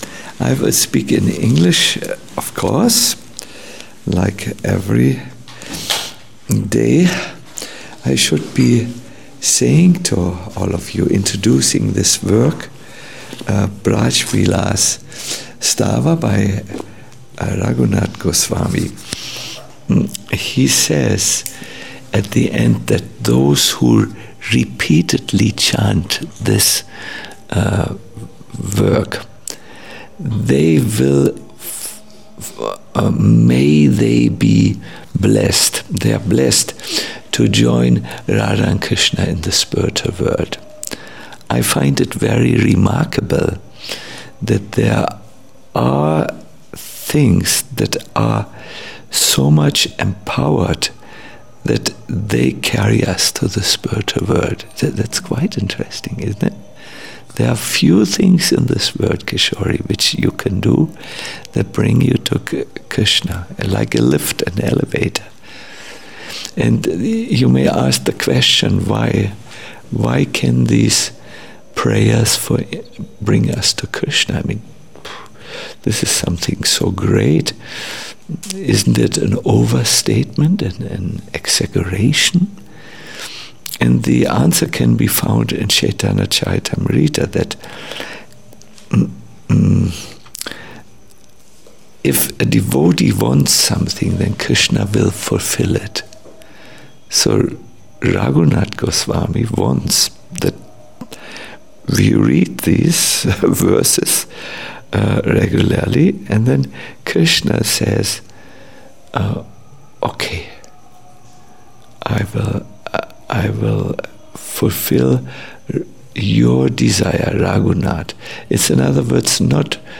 Kartik Katha 20 - a lecture